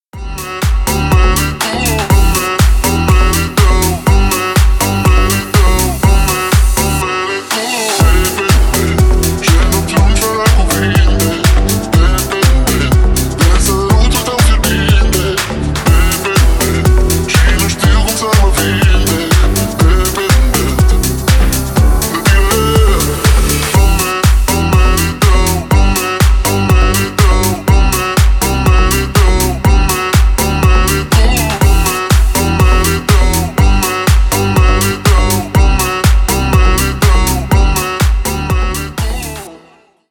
Танцевальные
громкие